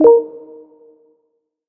menu-play-click.ogg